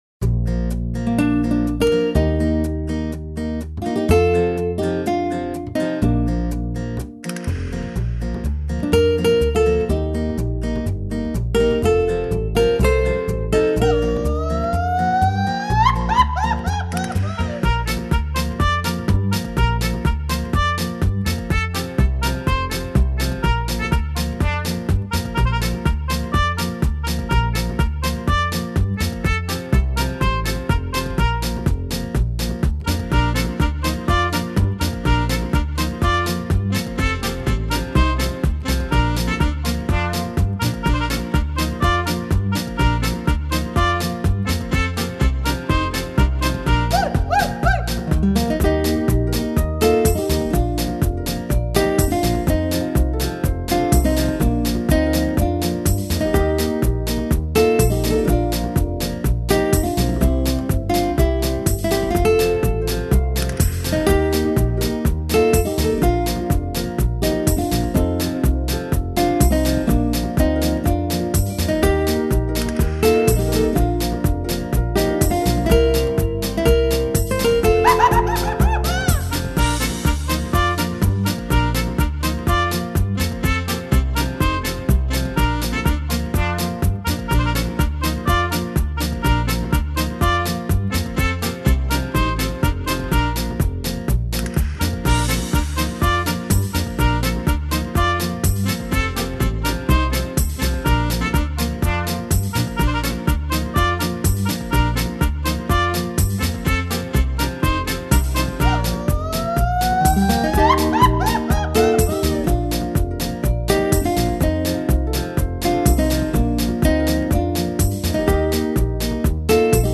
音乐的类型为轻音乐或者是NEW AGE
发烧级的录音效果，通过这个系列，我们可以更多的了解世界各地的音乐风格。